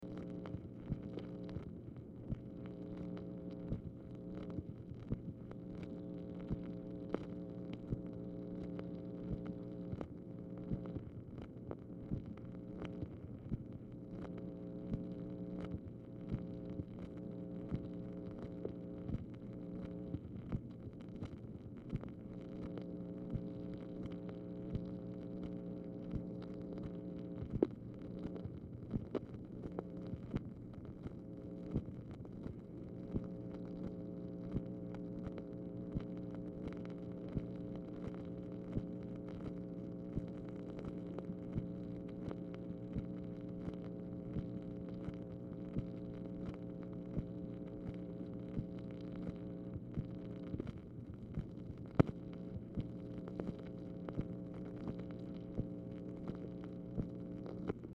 Telephone conversation # 7170, sound recording, MACHINE NOISE, 3/26/1965, time unknown | Discover LBJ
Format Dictation belt
Oval Office or unknown location
Speaker 2 MACHINE NOISE